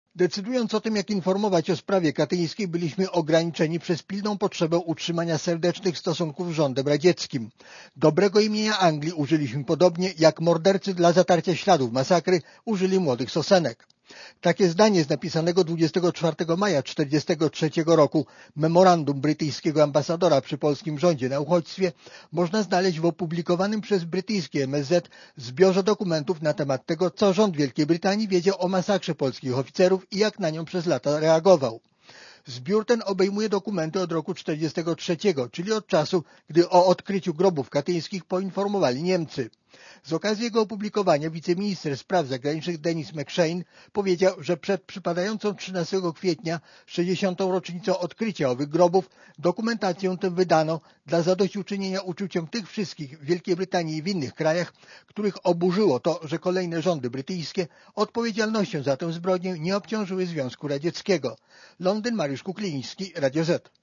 Korespondencja z Londynu (250Kb)